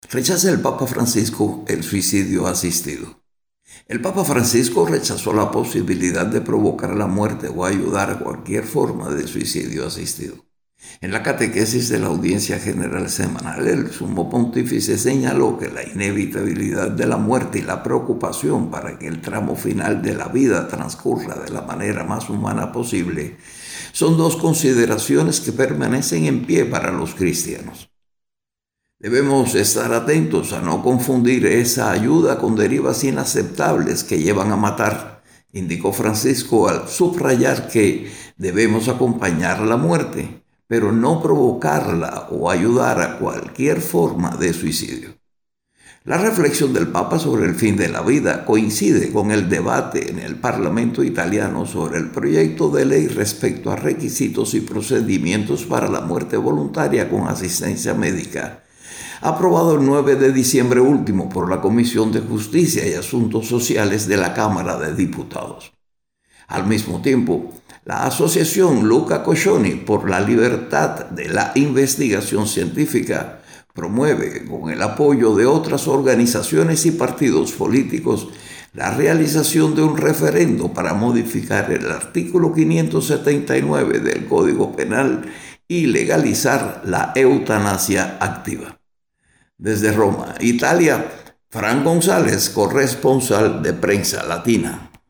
desde Roma